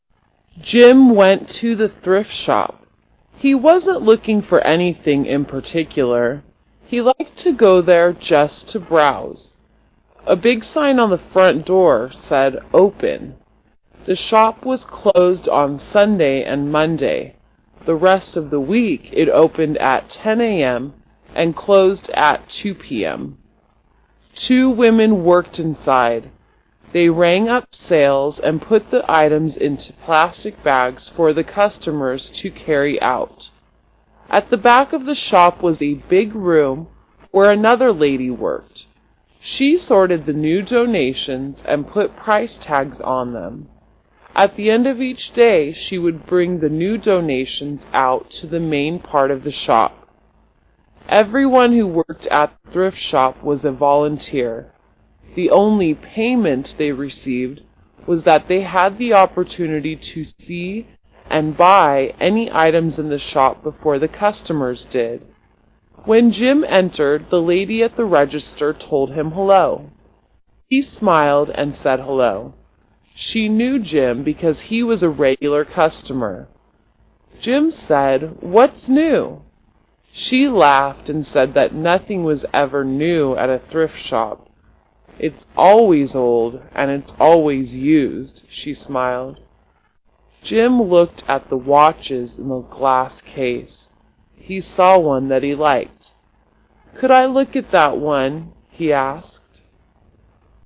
Slow  Stop audio